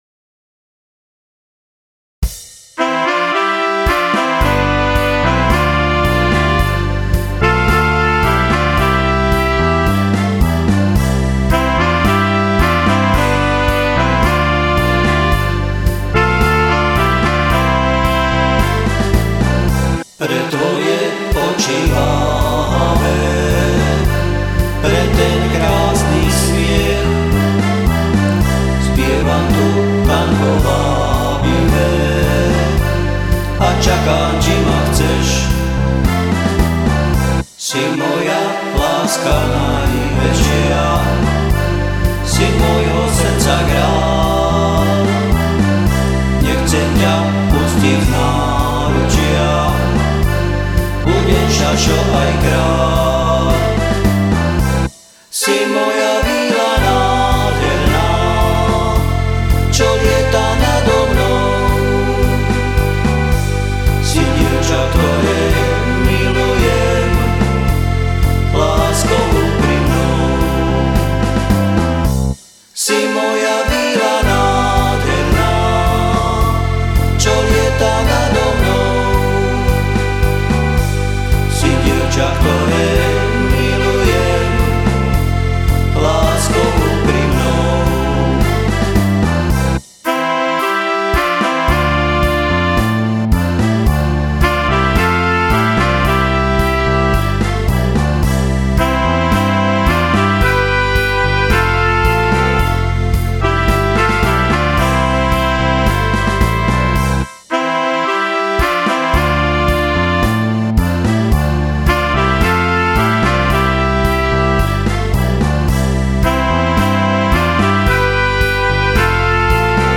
CD 1 - Prvé tango, aj keď som upustil od záhoráčtiny a dal som to po slovensky...